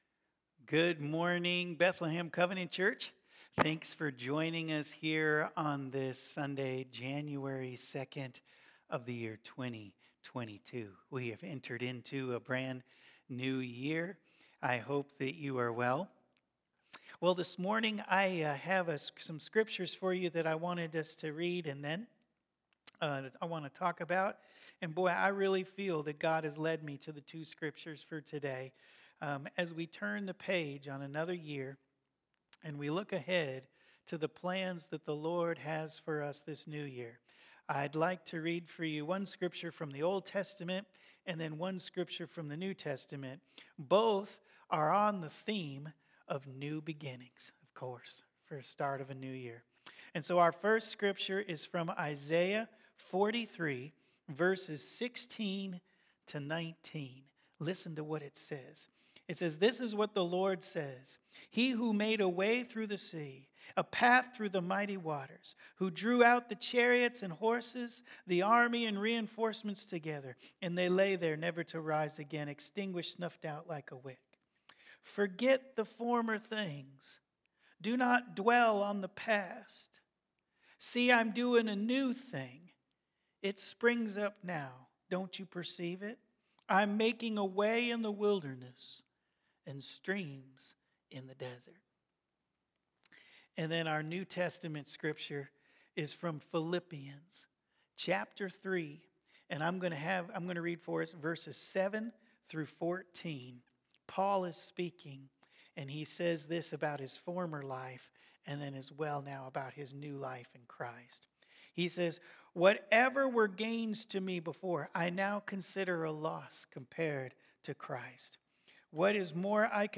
Bethlehem Covenant Church Sermons A New Dawn, A New Day Jan 20 2022 | 00:31:09 1x 00:00 / 00:31:09 Subscribe Share Spotify RSS Feed Share Link Embed